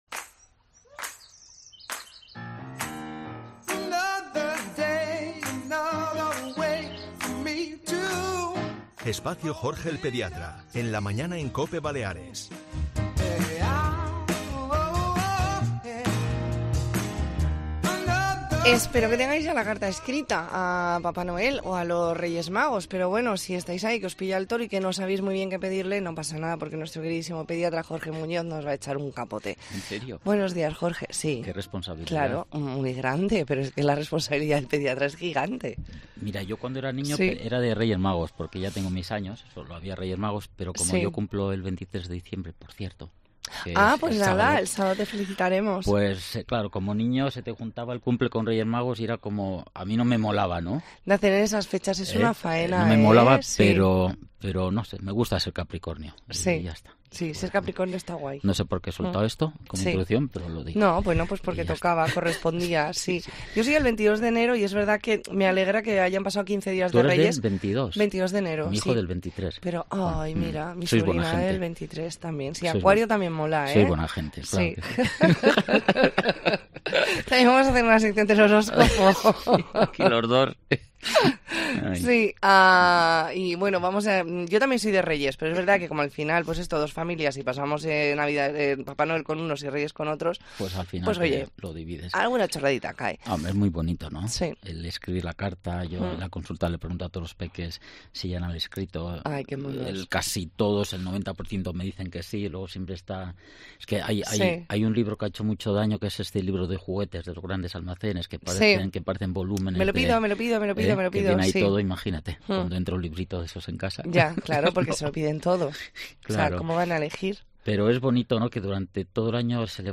Hoy escribir una carta muy diferente a Papá Noel. Entrevista en La Mañana en COPE Más Mallorca, martes 19 de diciembre de 2023.